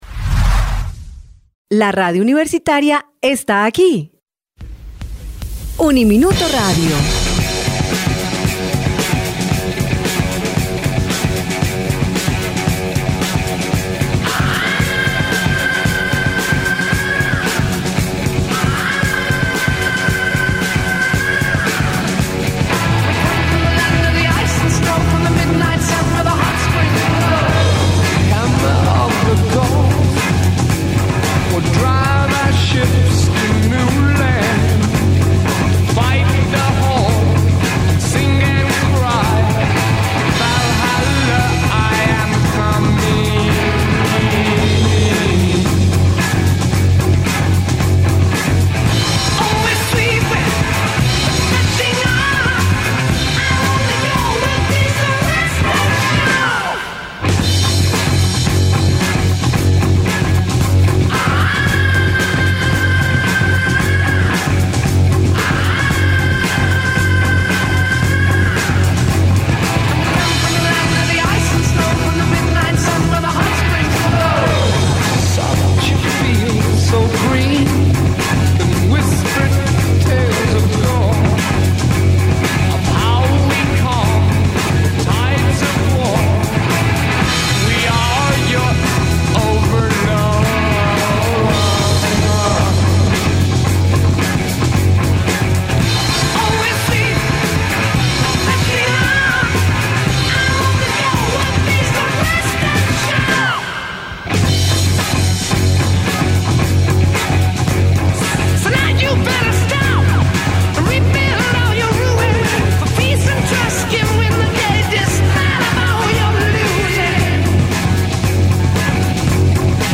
Kpa Prieto llega desde Puerto Rico con su Rock con trompeta, trombón y saxofón